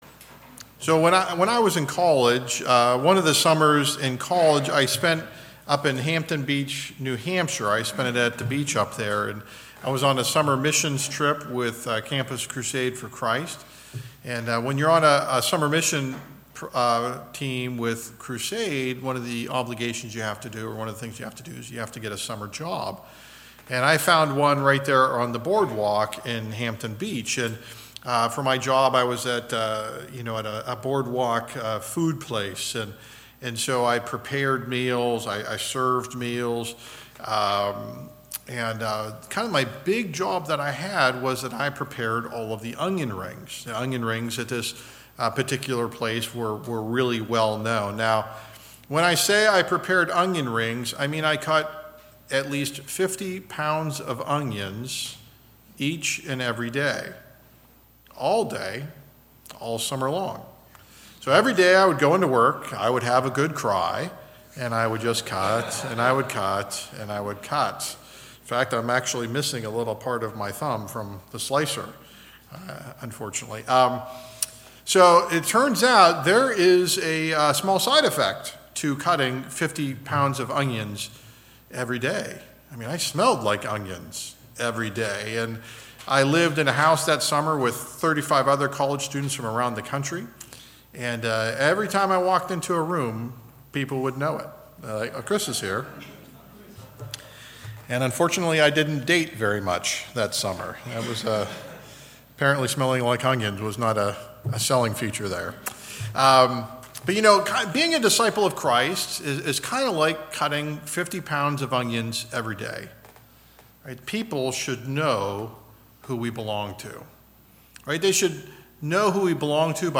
Essential Ingredients of Discipleship - Service